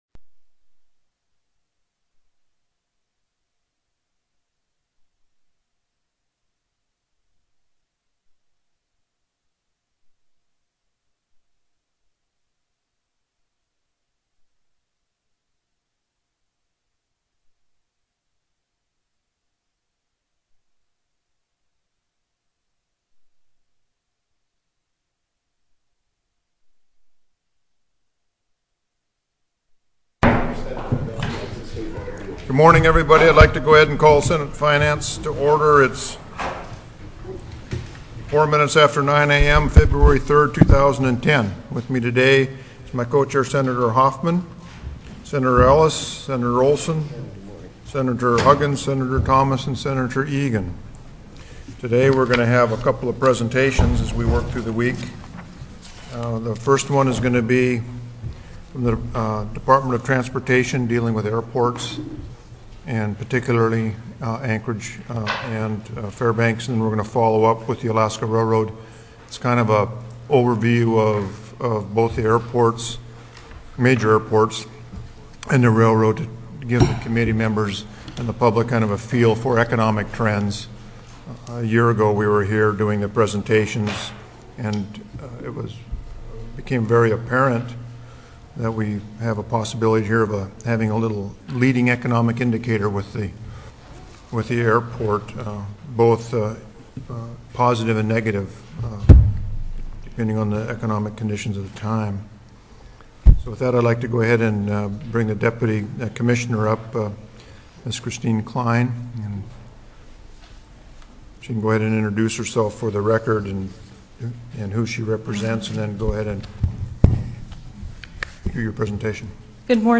02/03/2010 09:00 AM Senate FINANCE
TELECONFERENCED